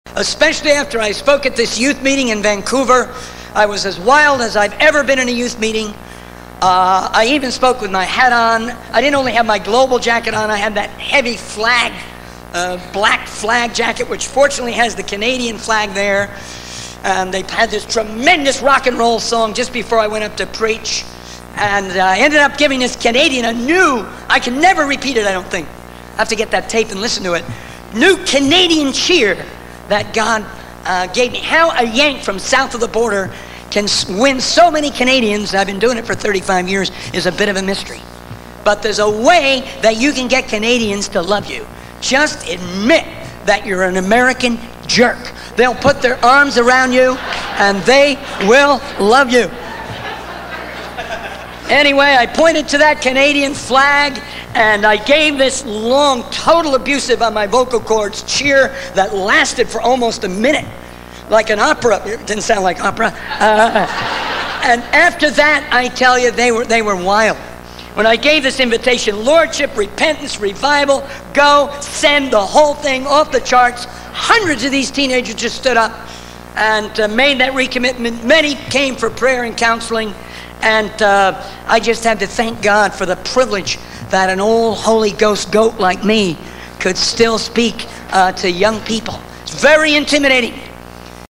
How to Get a Canadian to Love You (Comedy)